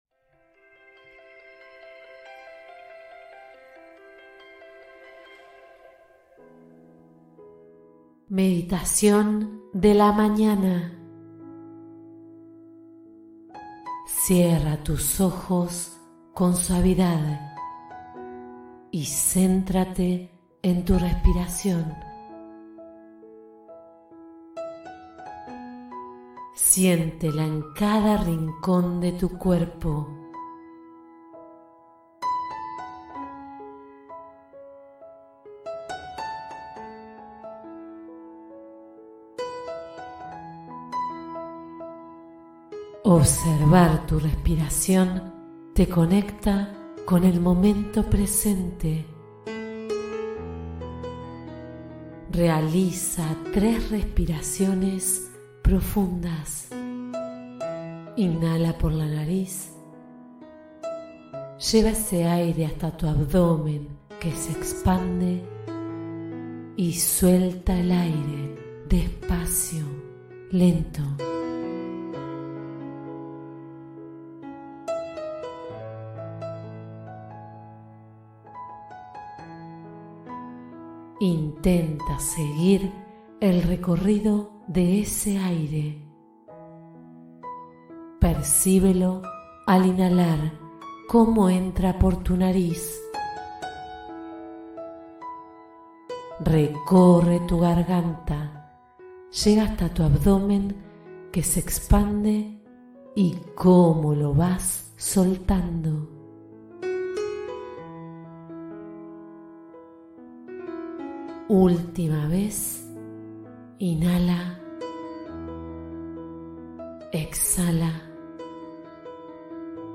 Comienza el día con actitud positiva y energía renovada con esta meditación